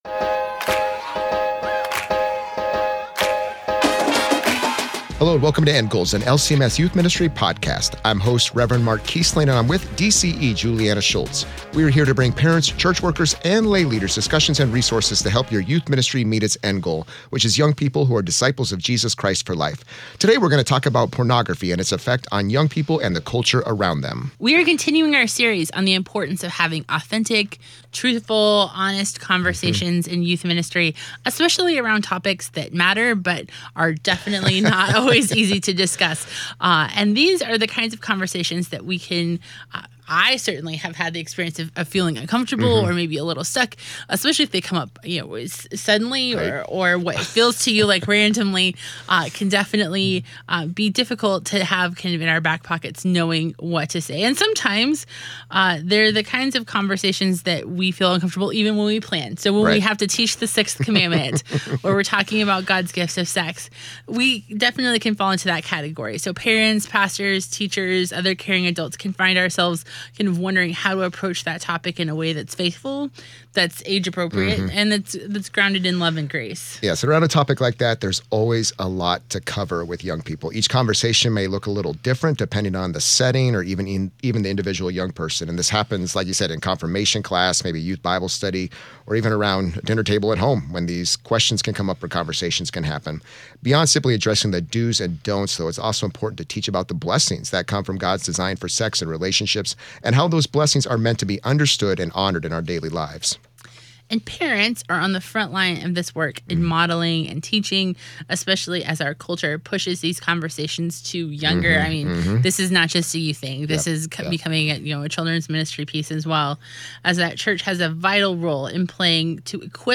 LCMS Youth Ministry Staff discuss practices for healthy youth ministry and interview practitioners who provide insight for experienced and new youth leaders.